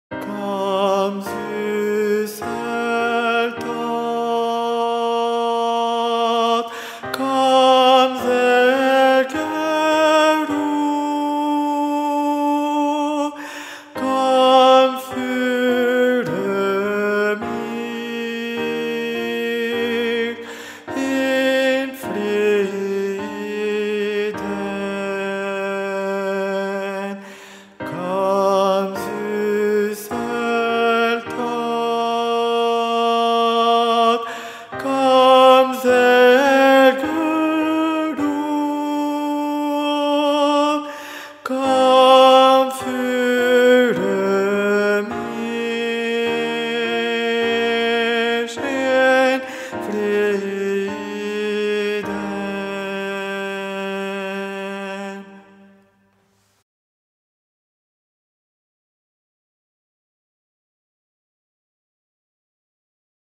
Versions chantées
Guide Voix Tenors Mp 3